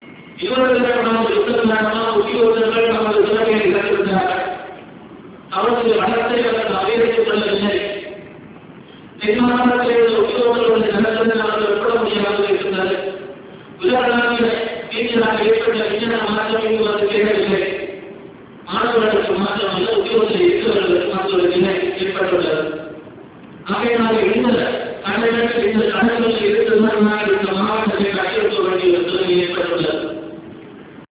TamilNet releases relevant parts of GA's speech in audio
TamilNet publishes the relevant parts of the speech by Government Agent of Jaffna at a meeting in Chavakachcheri on 27 December 2003 where he asserted that the Sri Lankan government “did not spend a red cent” on rehabilitation in the northern peninsula in the last two years.